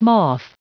Prononciation du mot moth en anglais (fichier audio)
Prononciation du mot : moth